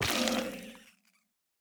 Minecraft Version Minecraft Version 1.21.5 Latest Release | Latest Snapshot 1.21.5 / assets / minecraft / sounds / block / sculk_sensor / break5.ogg Compare With Compare With Latest Release | Latest Snapshot